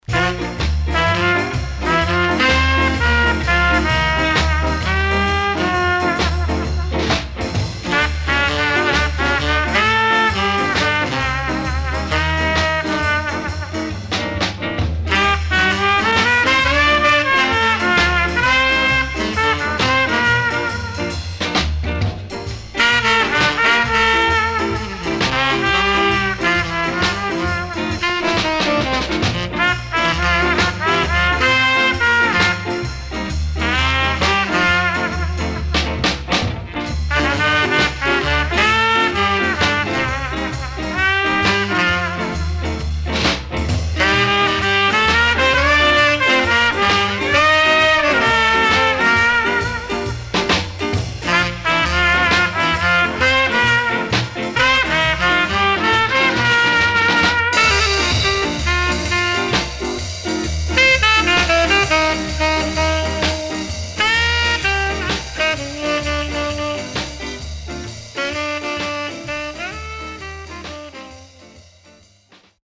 SIDE A:序盤ジリっとしたノイズ入りますが盤質は良好です。